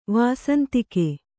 ♪ vāsantike